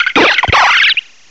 cry_not_trumbeak.aif